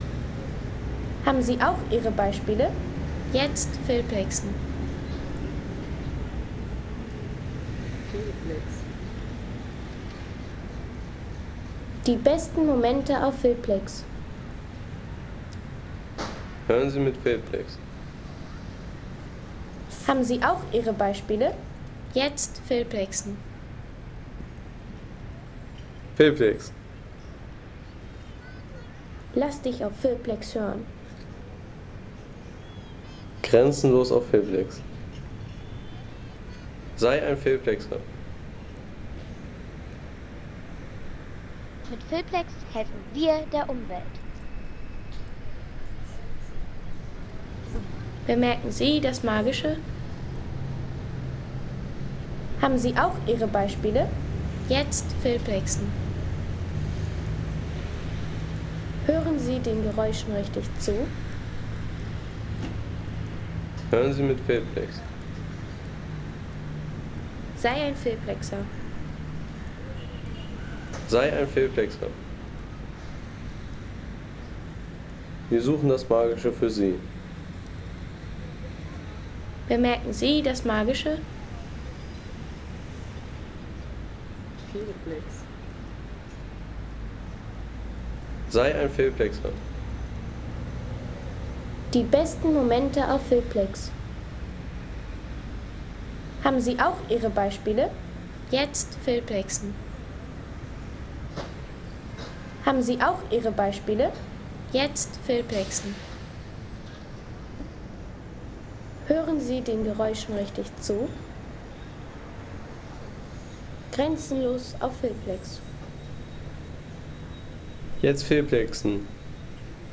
Kolosseum Kreuzung in Rom
Die Klangwelt rund um das Kolosseum in Rom.